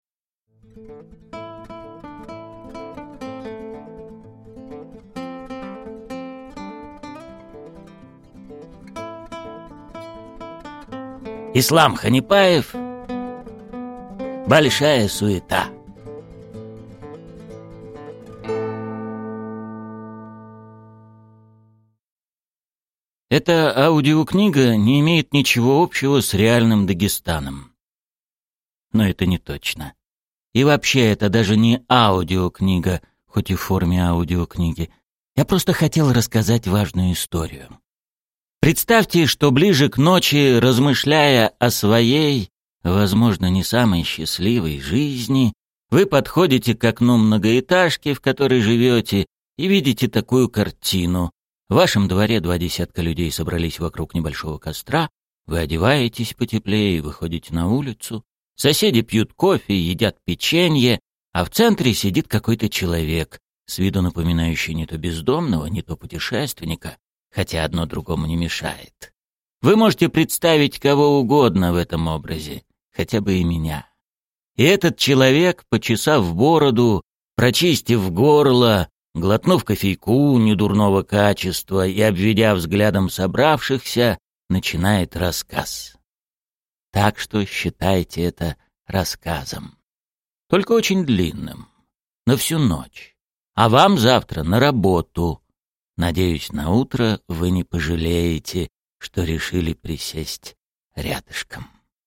Аудиокнига Большая Суета | Библиотека аудиокниг